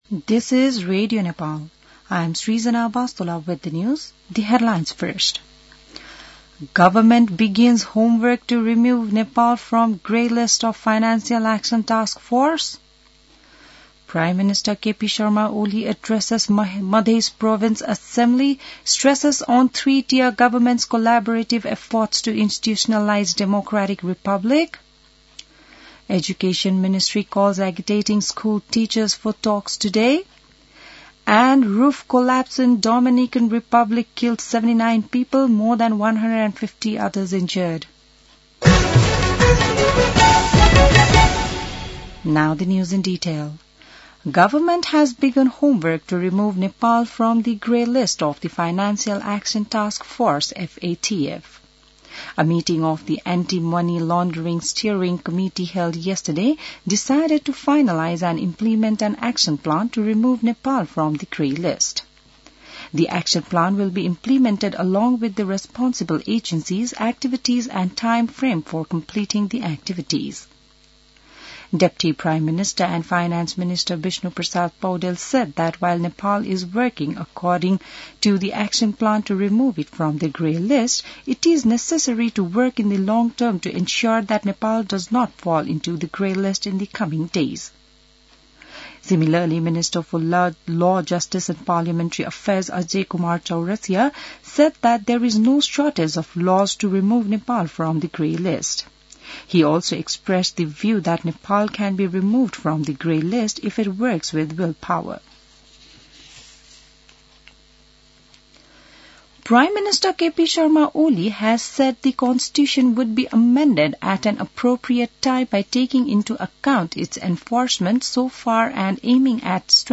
बिहान ८ बजेको अङ्ग्रेजी समाचार : २७ चैत , २०८१